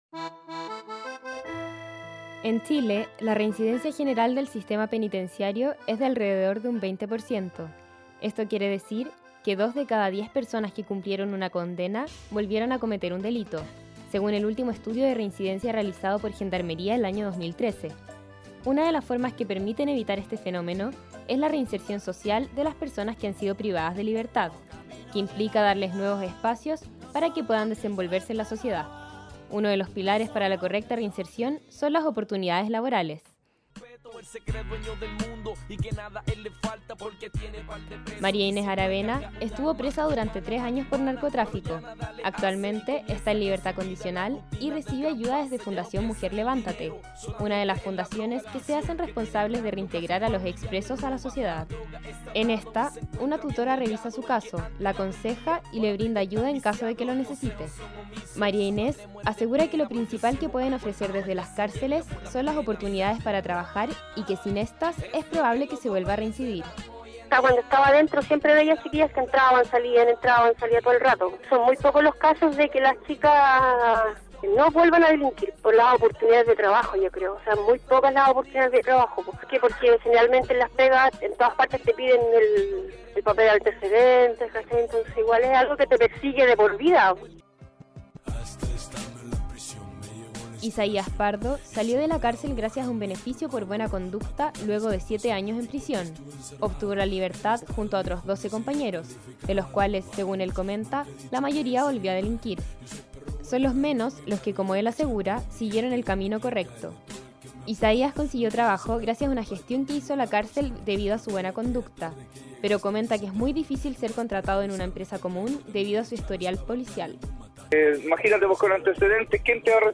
Reportaje «Reinserción social y laboral en Chile»